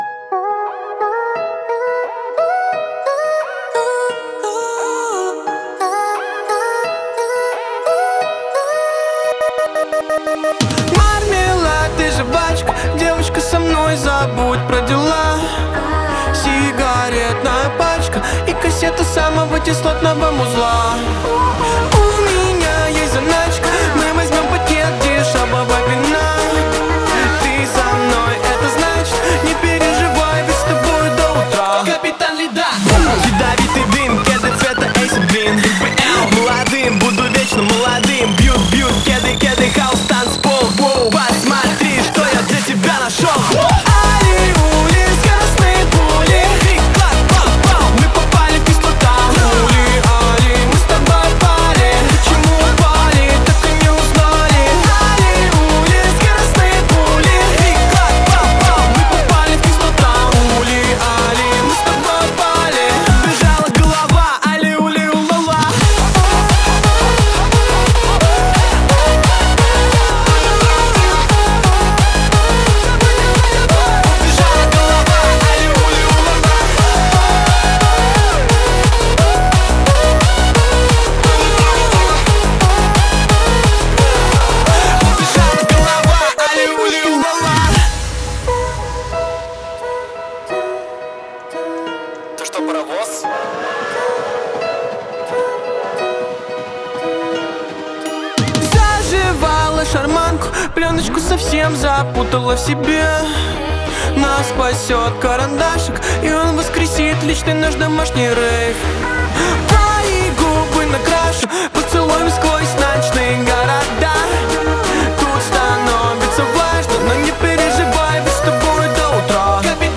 это яркий трек в жанре happy hardcore